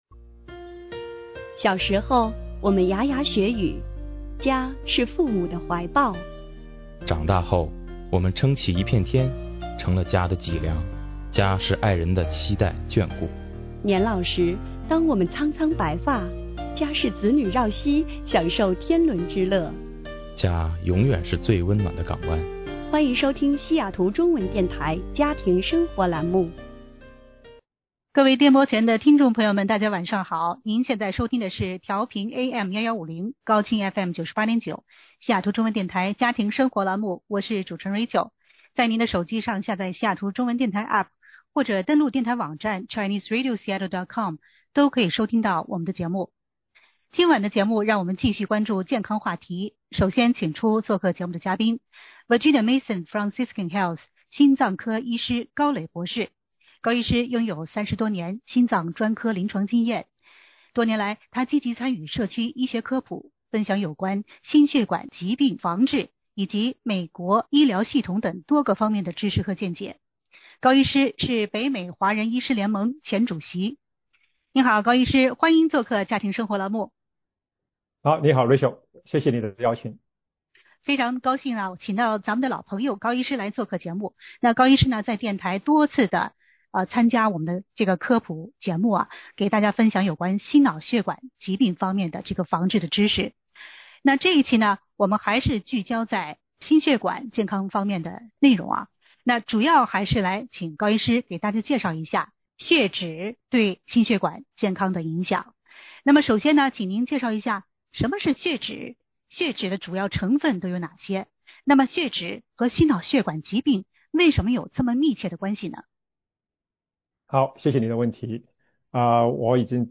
本周刚在西雅图中文电台做了相关话题的访谈，这是录音回放。